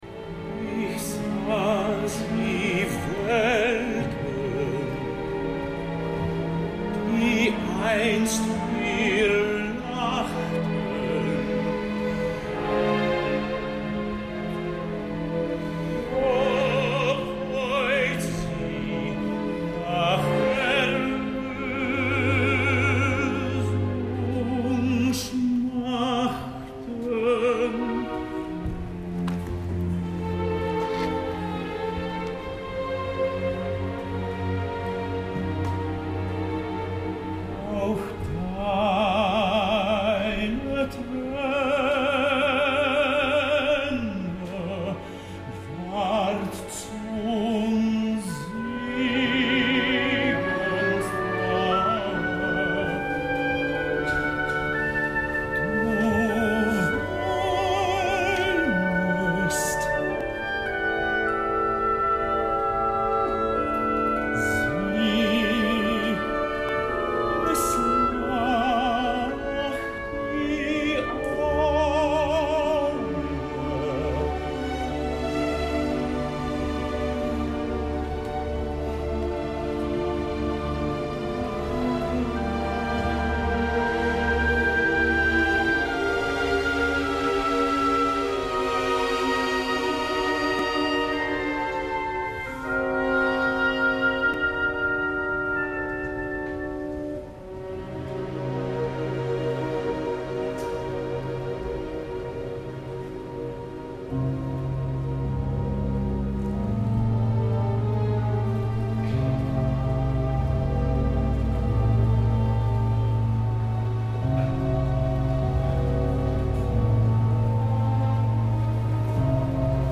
Ahir es va intentar aplaudir i també es va protestar.
Parsifal Bayreuth final acte 1er - Producció: Stefan Herheim
Música amb majúscules, música que sortia del màgic fossar i música que transmetien els cantants, avui si, cantants que interpretaven, en línies generals, amb musicalitat i expressivitat, allò que diu la partitura.